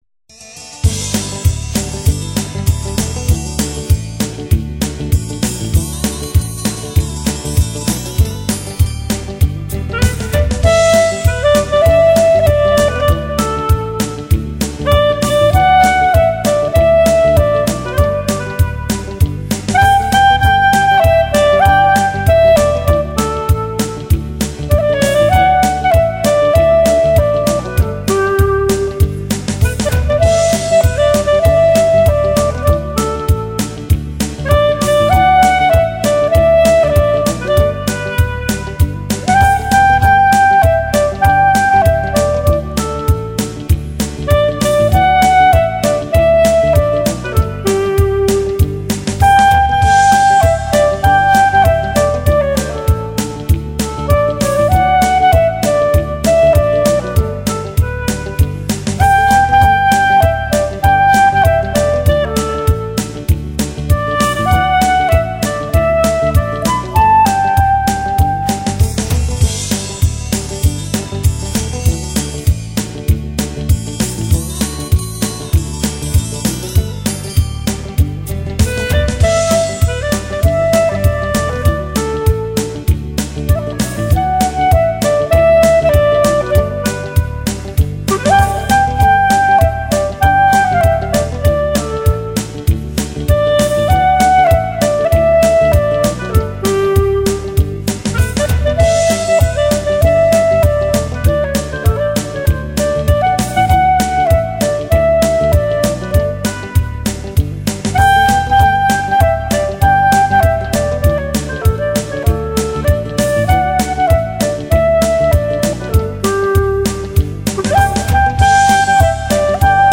，使乐器管内的空气柱开始振动，因而发出柔美的音色。